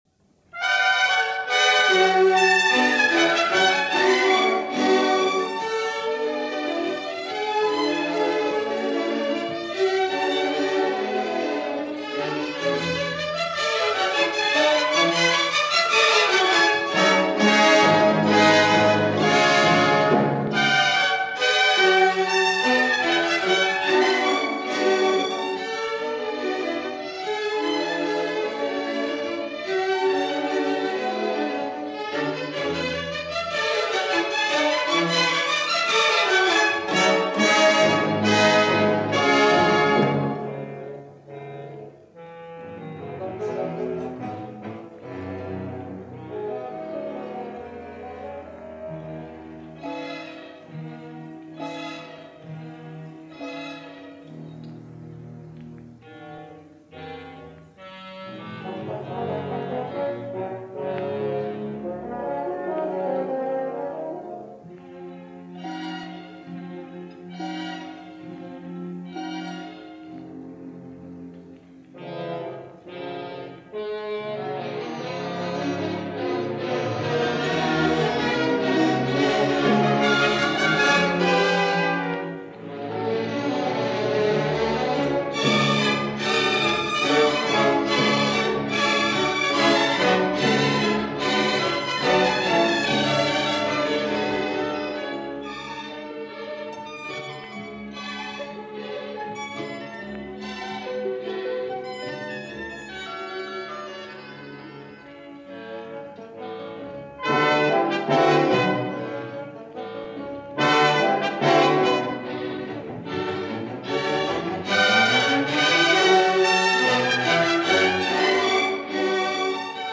Ravel: Menuet Antique – ORTF Philharmonic – Manuel Rosenthal,Conductor – ORTF Broadcast Studio session – 1963 – Gordon Skene Sound Collection –
So this performance isn’t particularly special; it’s been recorded by him several times for several labels, it is a broadcast performance which highlights another prolific side of his work – as a frequent conductor of most of the orchestras of France and a frequent performer via French Radio and TV, which I suspect many of his performances have survived.